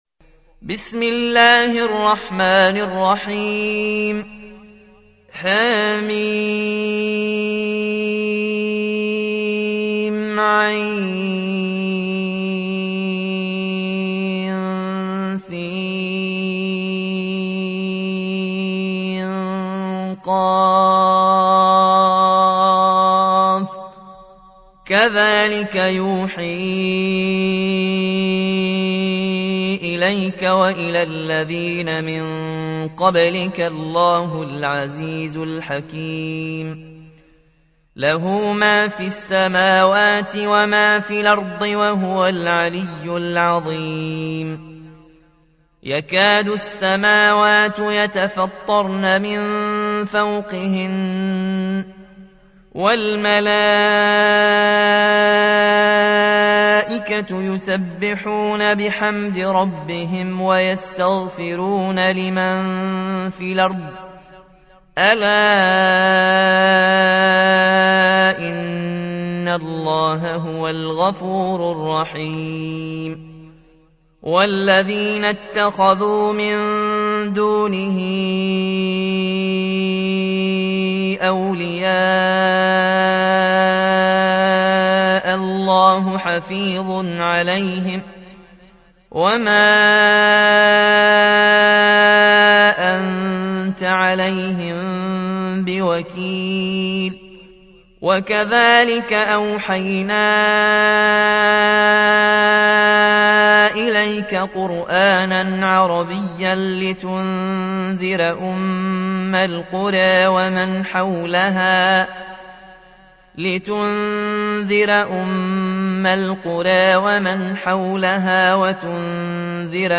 42. سورة الشورى / القارئ